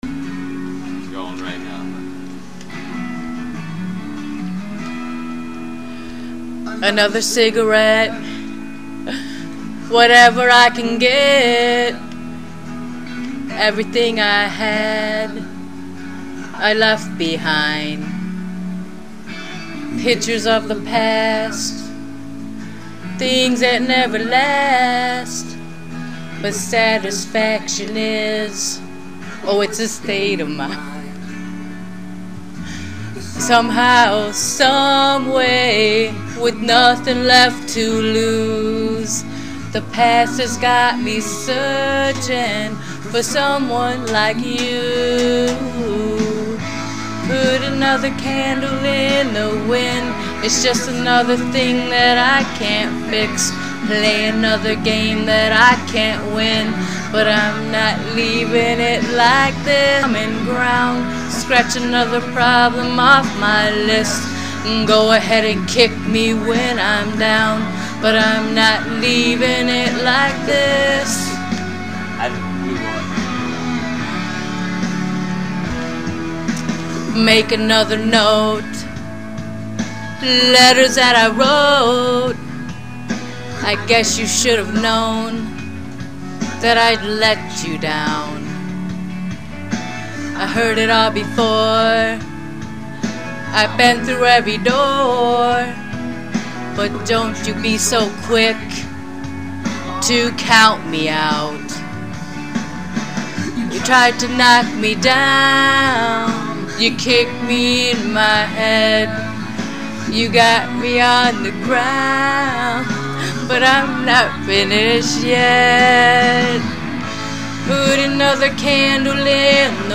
Me singing/\
Hip-hop
Pop